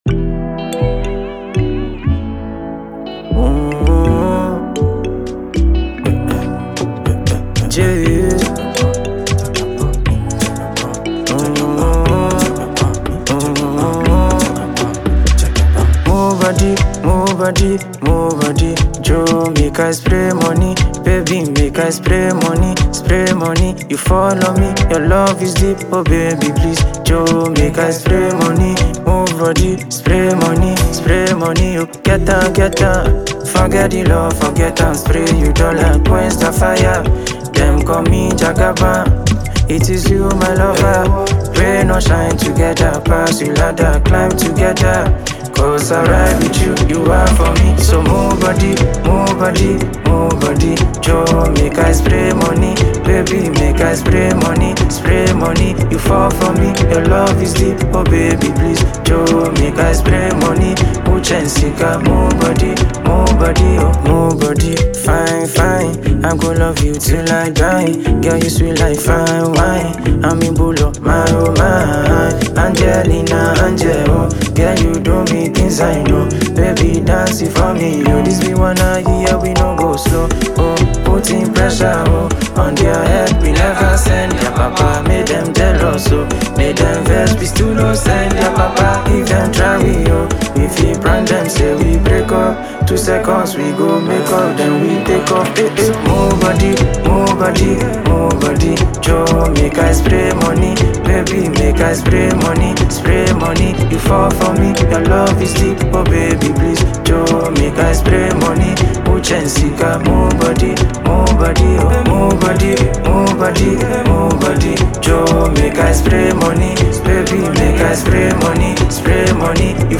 high-energy Ghanaian Afrobeat/dance record
lively vocals and a confident performance
Genre: Afrobeat / Dance